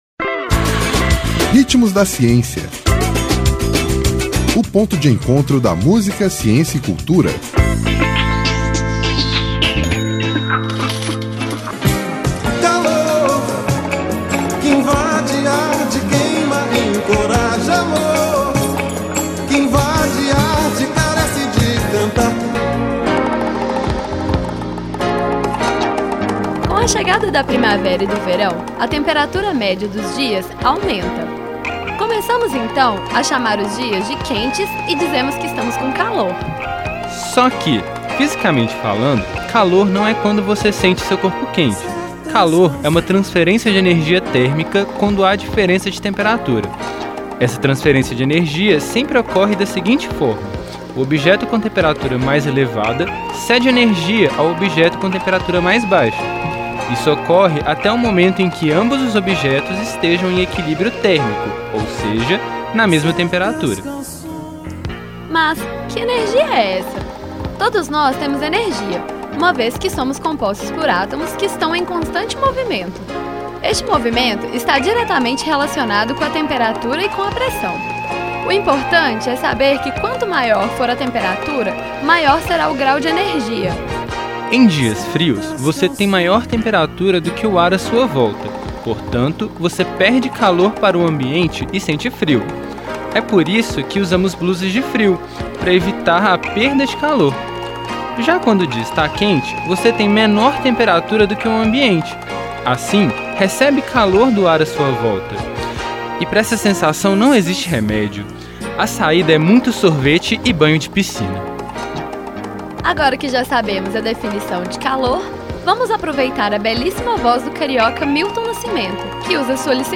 Intérprete: Milton nascimento